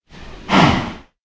sounds / mob / cow / say4.ogg